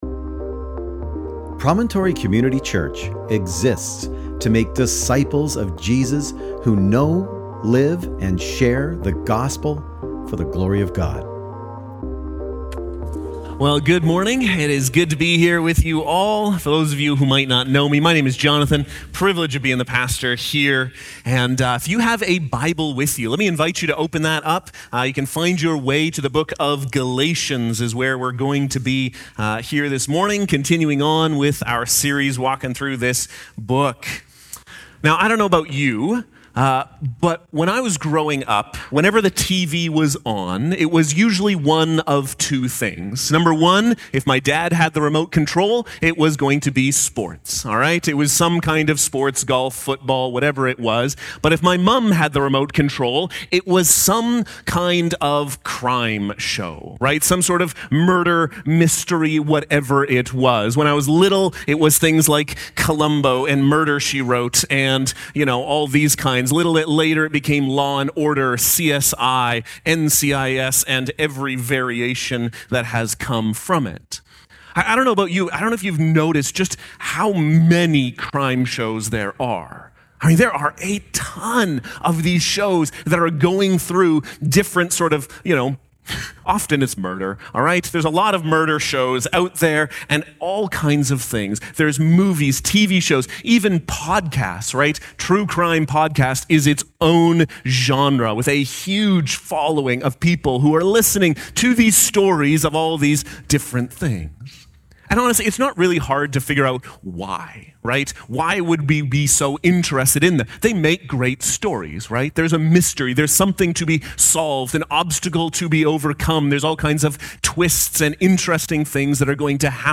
Sermon Text: Galatians 2:15-16